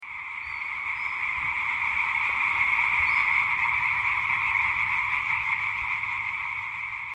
Laubfrosch-Männchen (Hyla arborea)
Amphibien-Laubfroesche.mp3